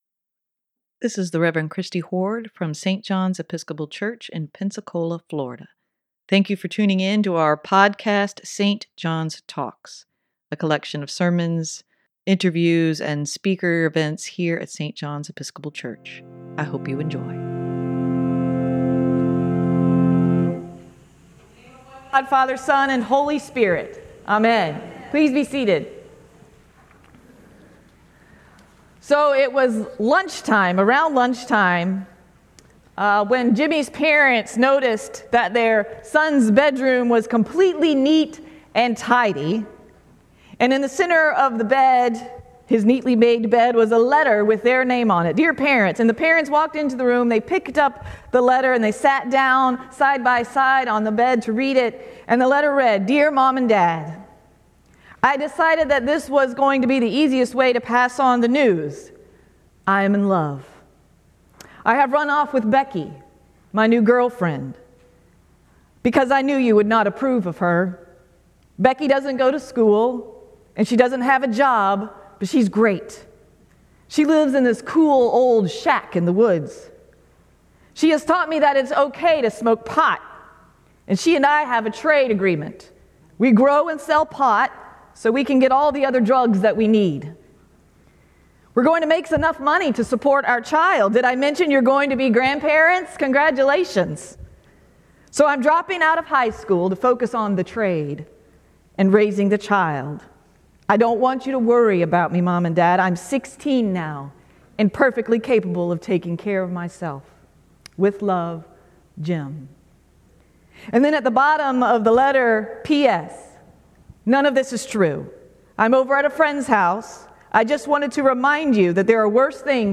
Sermon for Sept. 3, 2023: Christian principles for everyday living - St. John's Episcopal Church